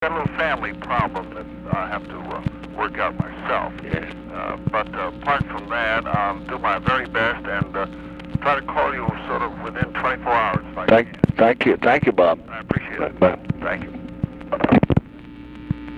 Conversation with ROBERT MURPHY, April 23, 1968
Secret White House Tapes